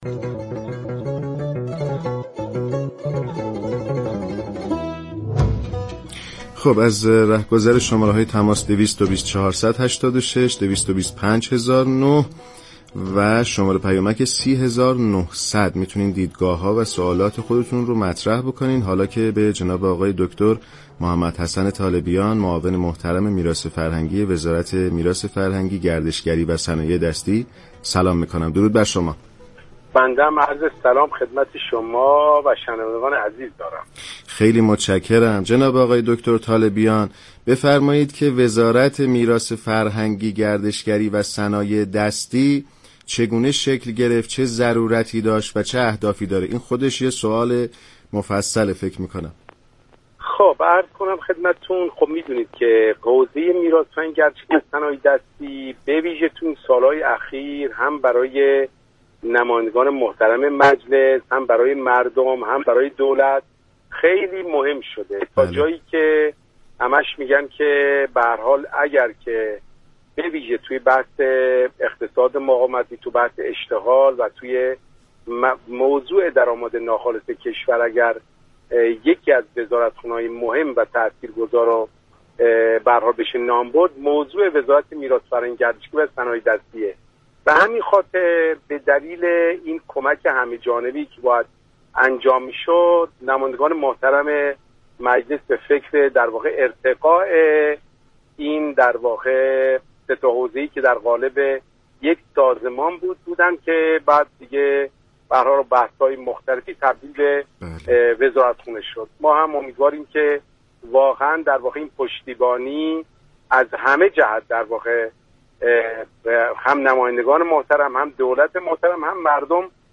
محمدحسن طالبیان معاون میراث فرهنگی وزارت میراث فرهنگی، صنایع دستی و گردشگری در برنامه كافه هنر رادیو ایران گفت : دستگاه های متولی باید مسئولیت های خود را درك كنند